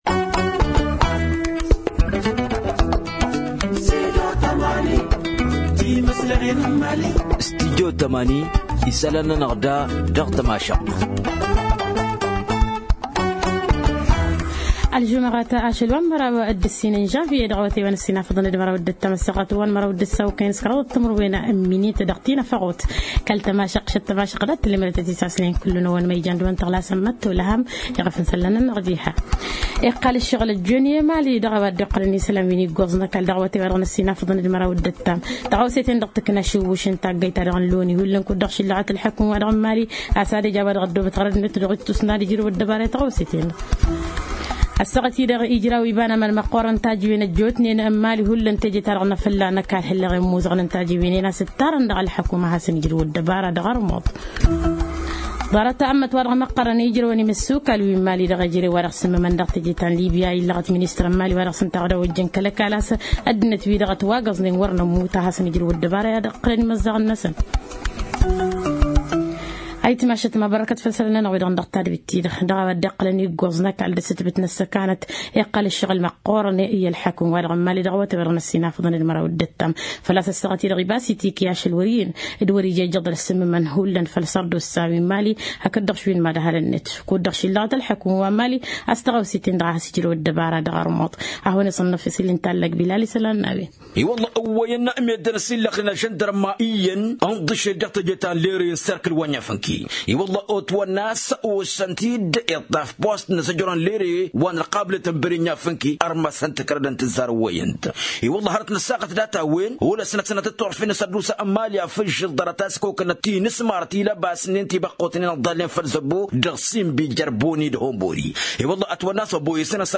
Journal en tamasheq: Télécharger